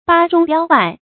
弸中彪外 péng zhōng biāo wài 成语解释 弸：充满；彪：文采。指人内有才德，则有文采，自然外露。